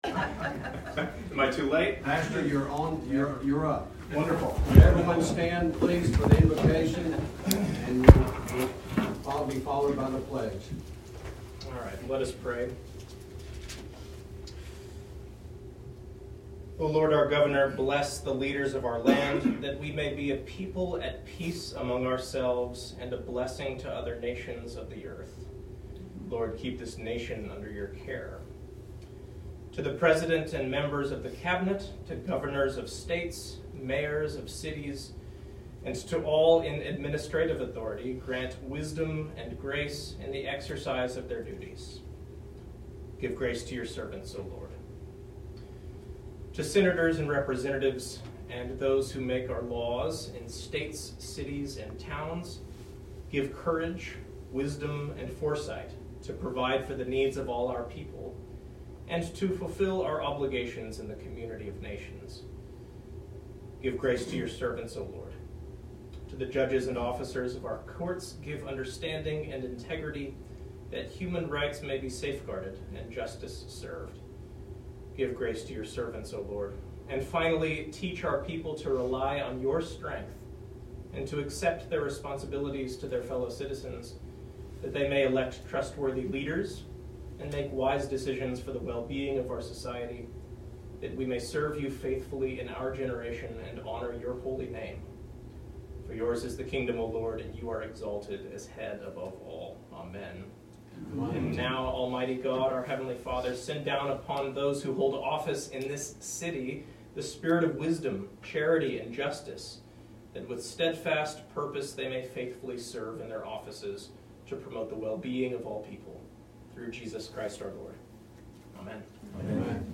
25 October 2022 Council Meeting